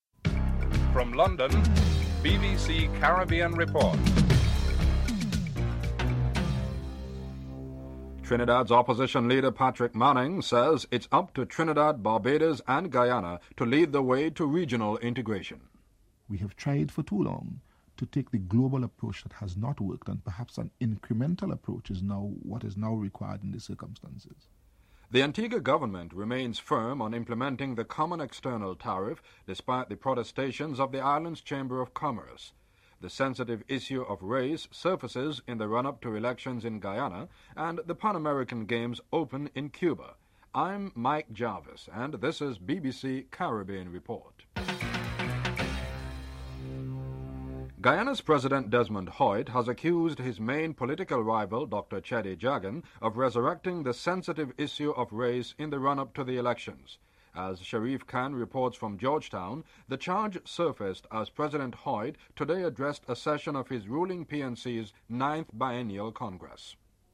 1. Headlines (00:00-00:46)